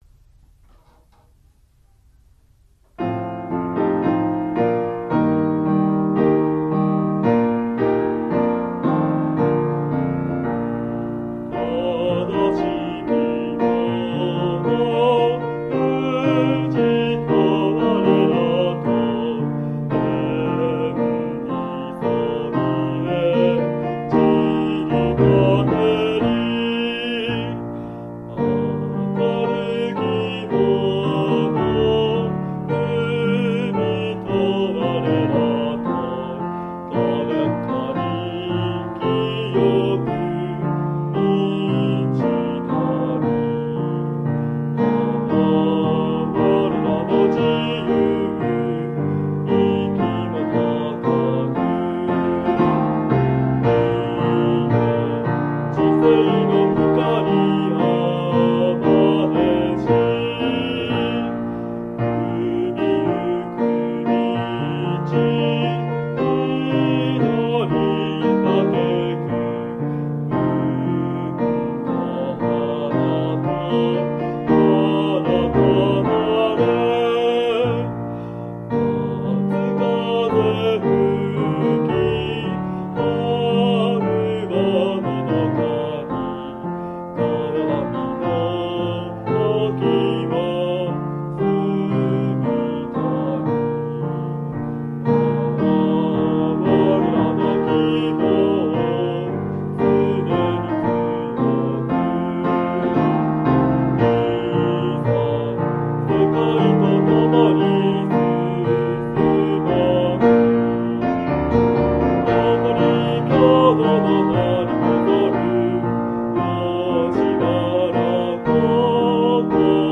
吉原高校校歌　（歌有り（男性））　MP３ファイルは、こちら
吉原高校 校歌(男性)[MP3：1.29MB]